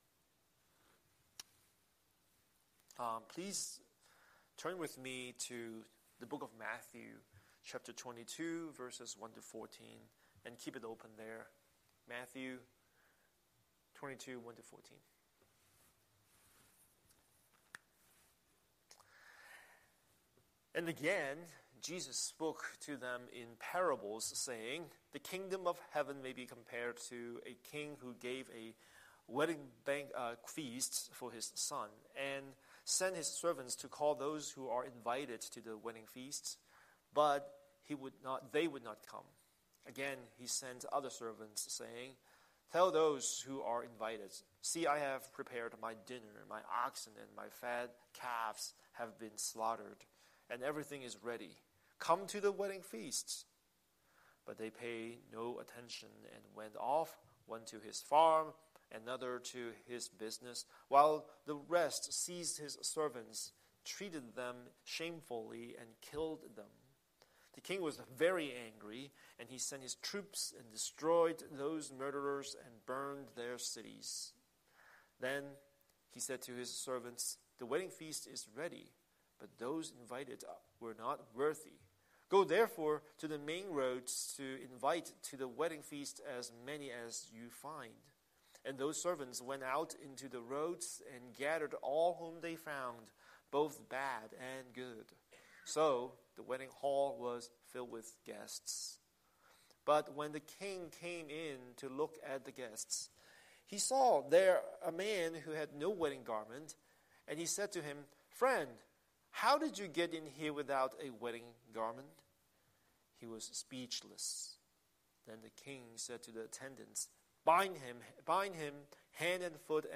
Scripture: Matthew 22:1-14 Series: Sunday Sermon